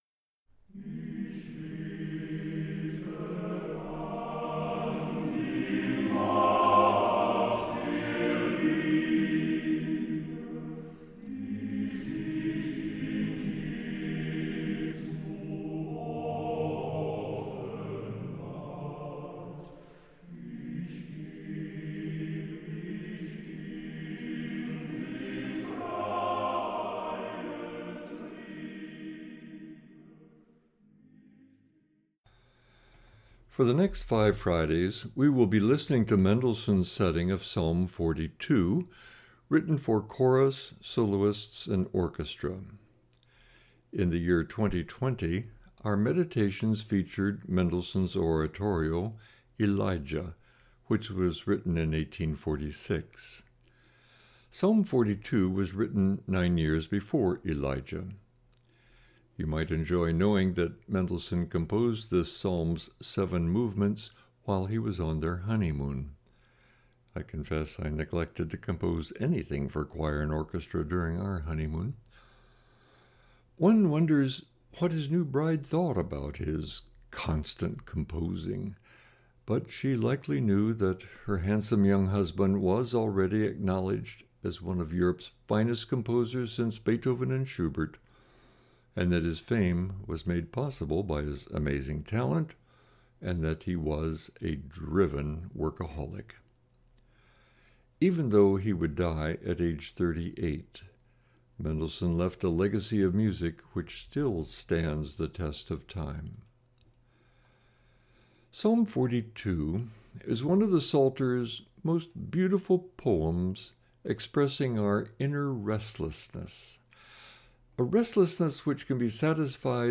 Meditation - Point Grey Inter-Mennonite Fellowship